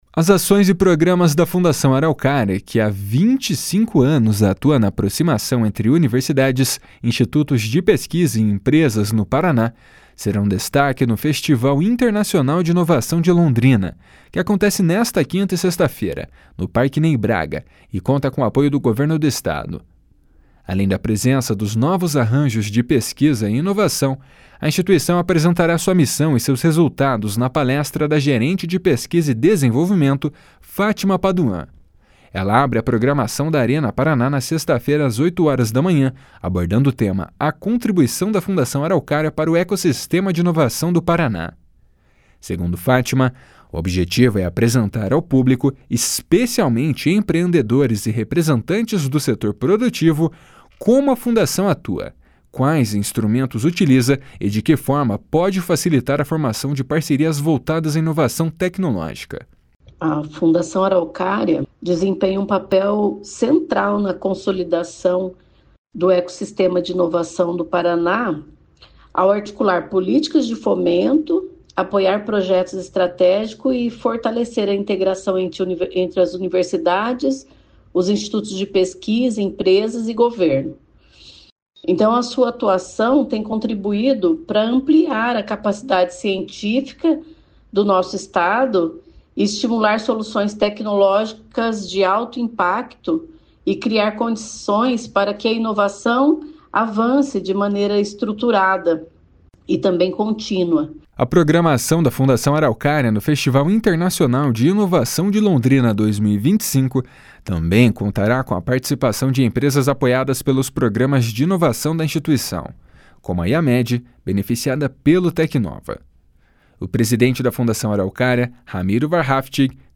O presidente da Fundação Araucária, Ramiro Wahrhaftig, reforça a importância do Festival Internacional de Inovação de Londrina no estímulo à inovação no Estado. // SONORA RAMIRO WAHRHAFTIG //